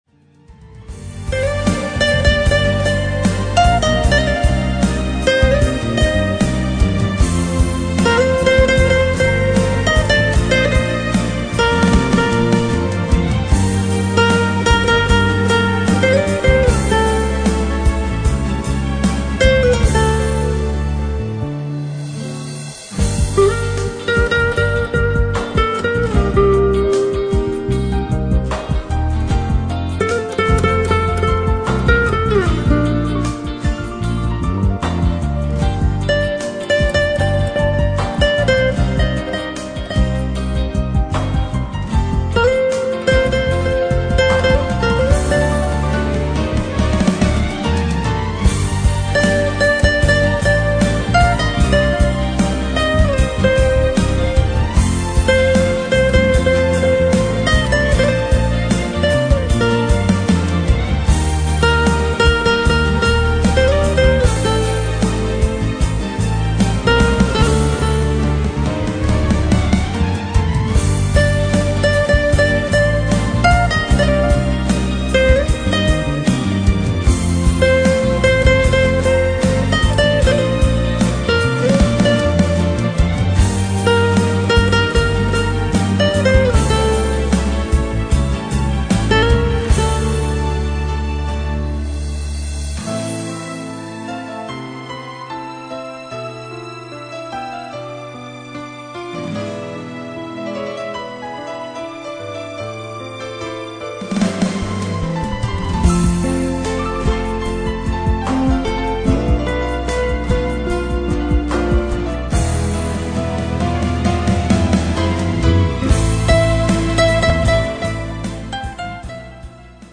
guitare accoustique